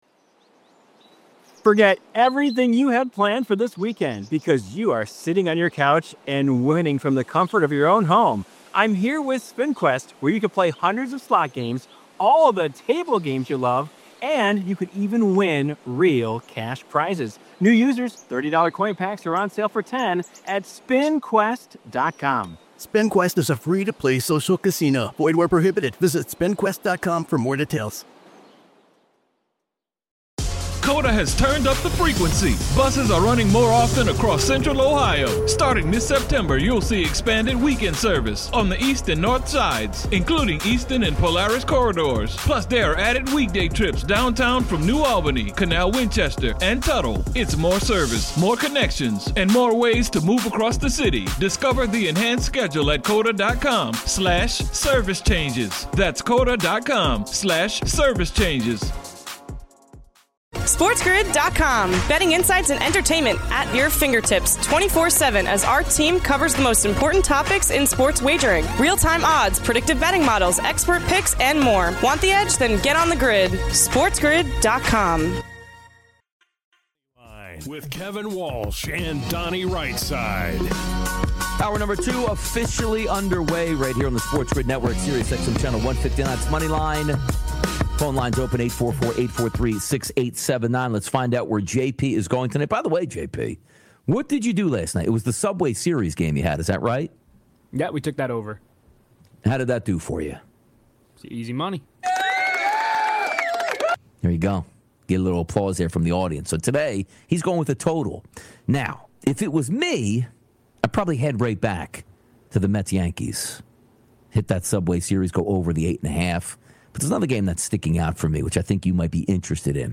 He previews every game and his best bets and props from each. He takes your calls, gives you his best bets, and a whole lot more on today's Moneyline!